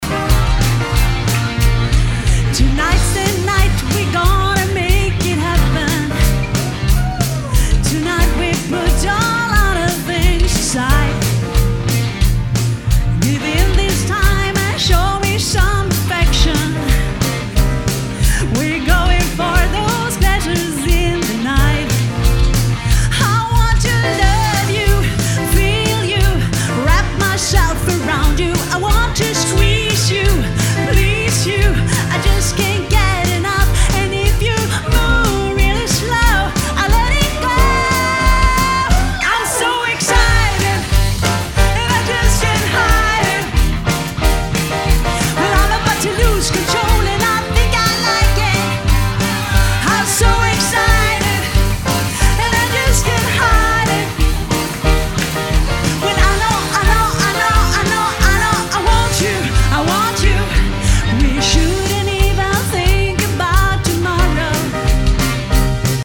sång
trummor
keyboards
gitarr
Alltid 100% live!
• Coverband